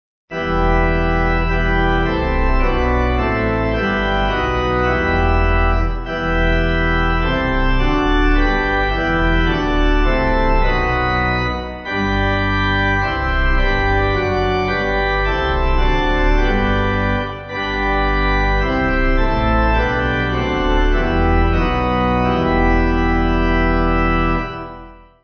Organ
(CM)   3/Em